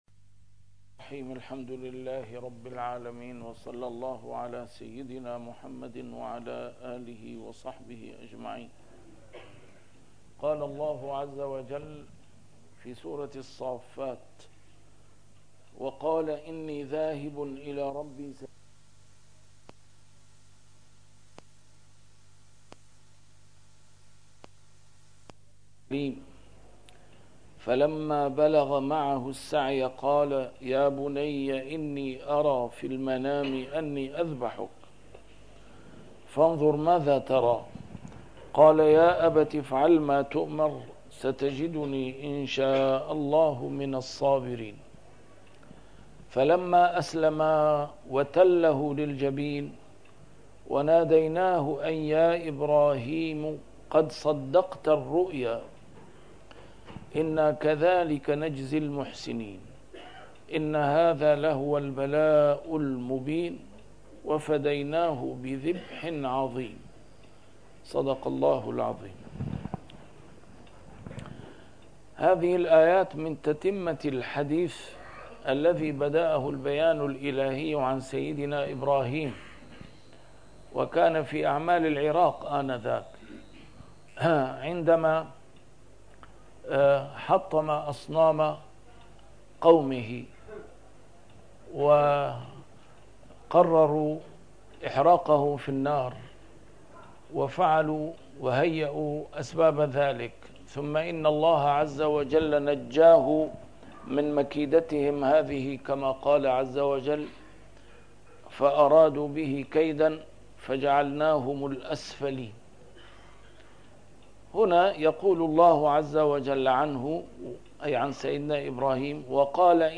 A MARTYR SCHOLAR: IMAM MUHAMMAD SAEED RAMADAN AL-BOUTI - الدروس العلمية - تفسير القرآن الكريم - تسجيل قديم - الدرس 455: الصافات 099-107